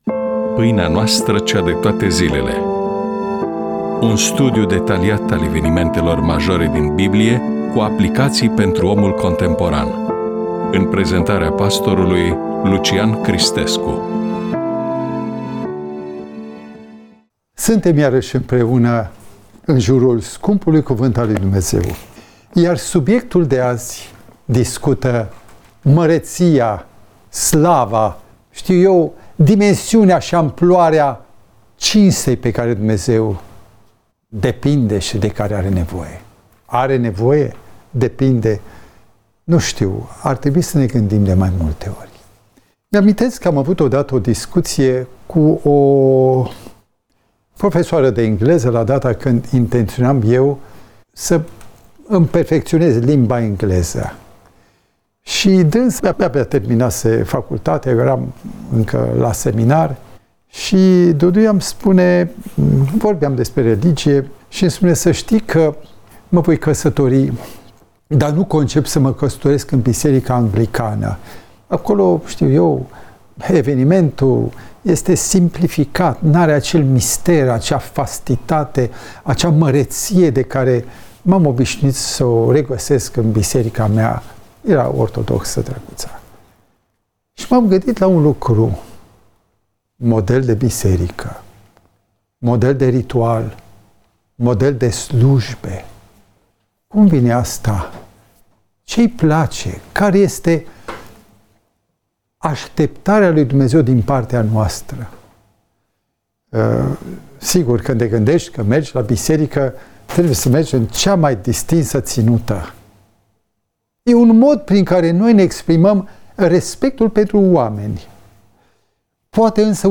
EMISIUNEA: Predică DATA INREGISTRARII: 31.07.2025 VIZUALIZARI: 64